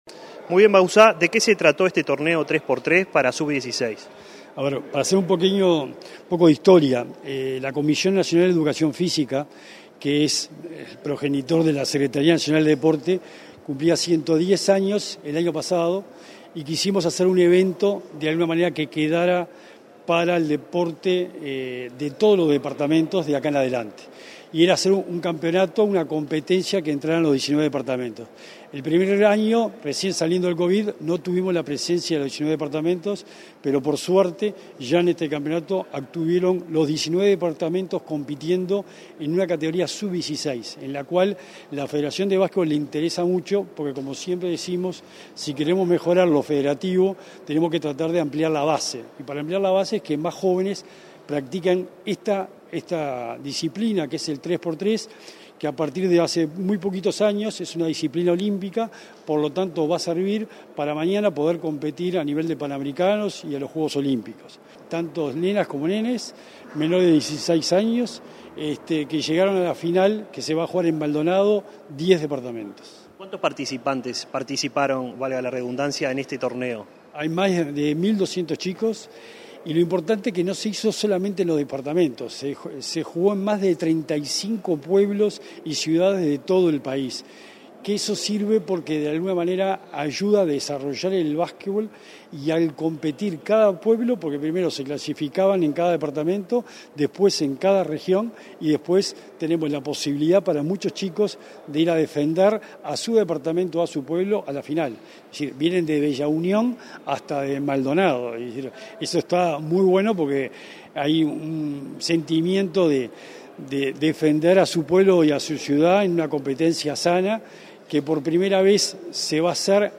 Entrevista al secretario nacional del Deporte, Sebastián Bauzá
Tras el evento, el secretario nacional del Deporte, Sebastián Bauzá, realizó declaraciones a Comunicación Presidencial.